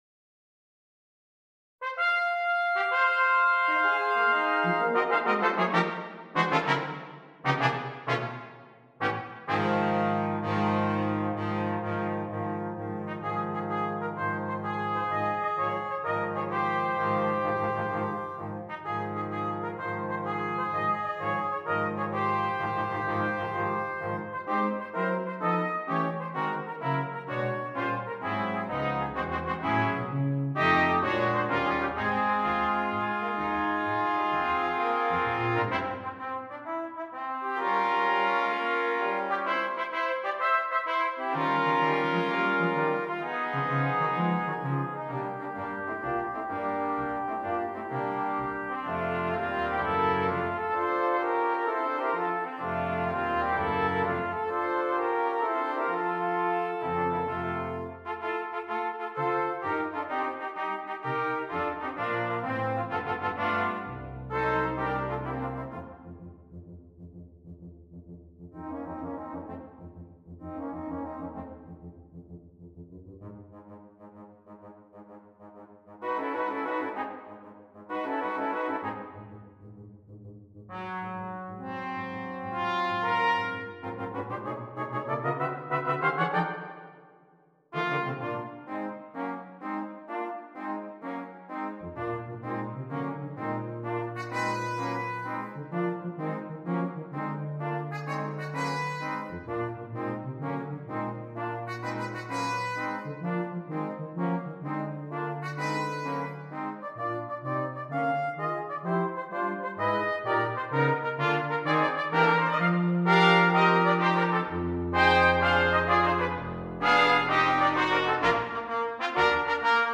Brass Quintet (optional Percussion)